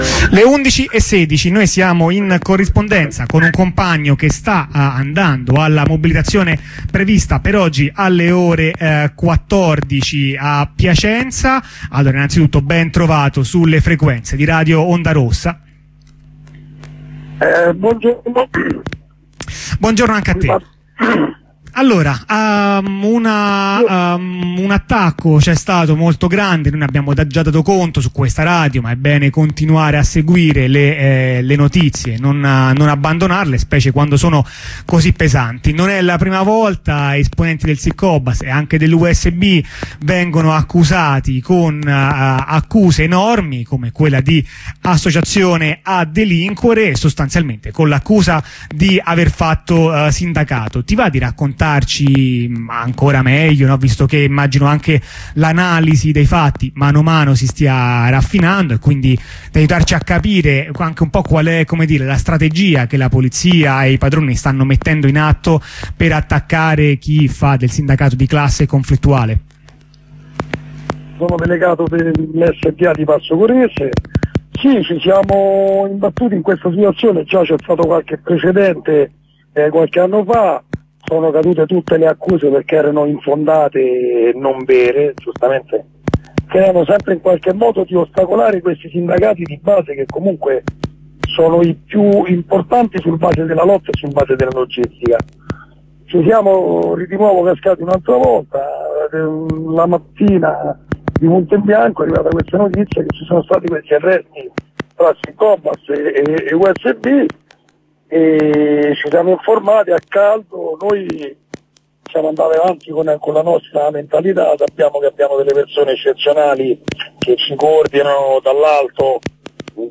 Corrispondenza con un compagno dei Cobas settore privato